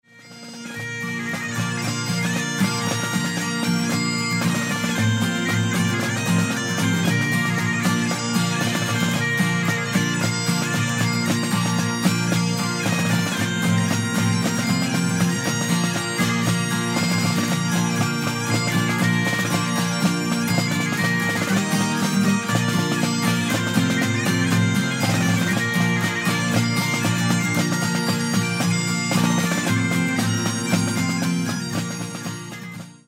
My repertoire includes both Scottish and Irish tunes.
Bagpiper and Drummer
Bagpipes & Drums
Bagpipes.mp3